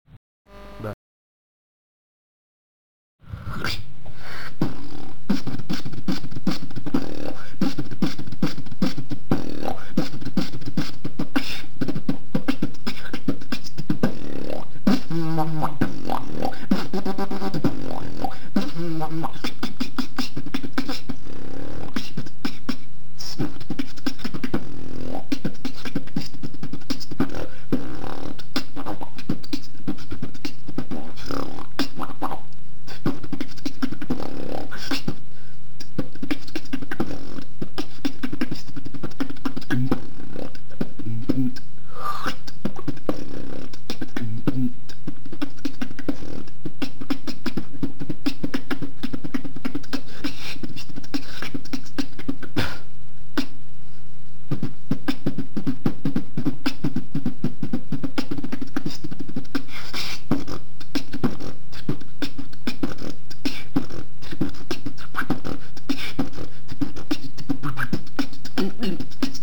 21.05.2011 состоялся баттл
на нашем русском сервере Вентры